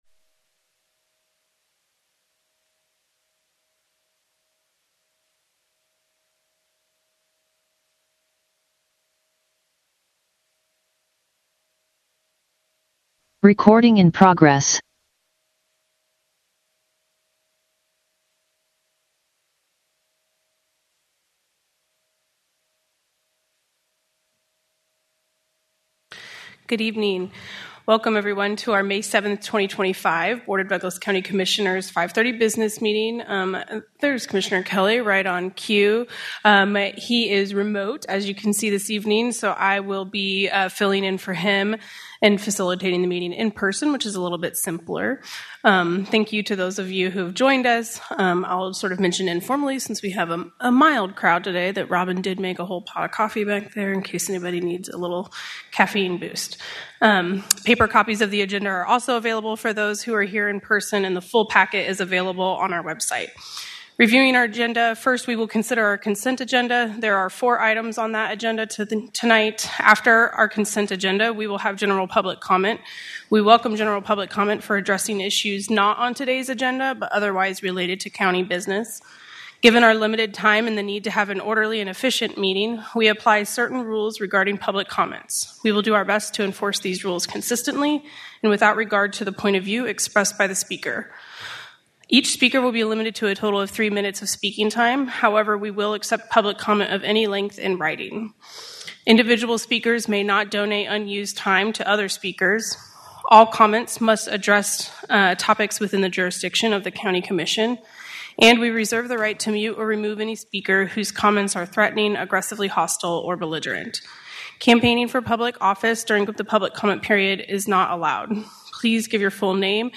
Commission Board Meeting Wed, May 7 2025 5:30 PM | Douglas County KS
Business Meeting 05.07.25.mp3